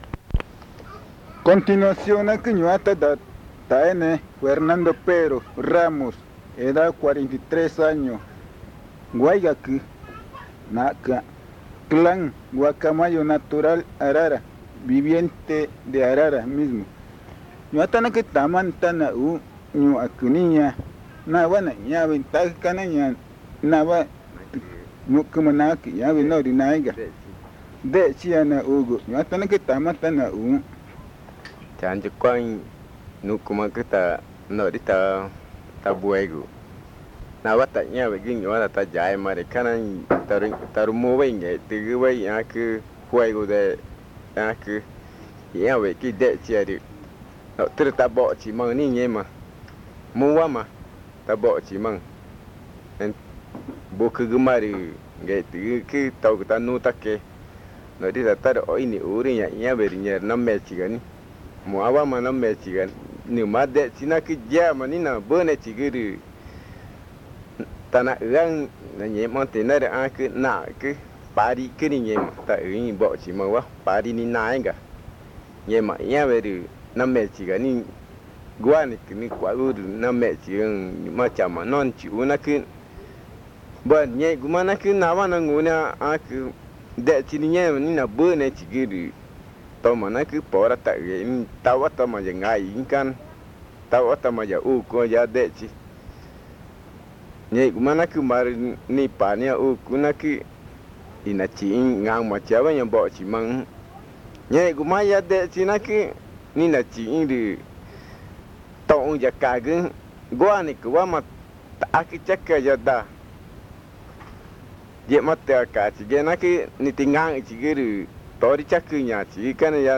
Arara, Amazonas (Colombia)